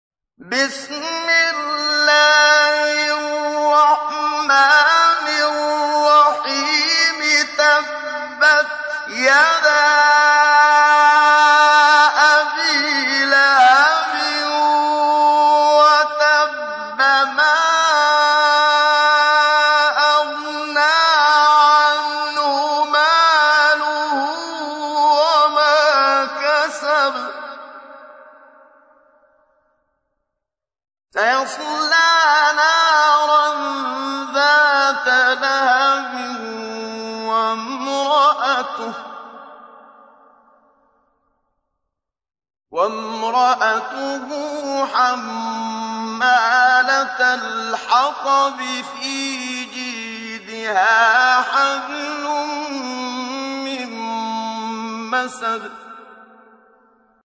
تحميل : 111. سورة المسد / القارئ محمد صديق المنشاوي / القرآن الكريم / موقع يا حسين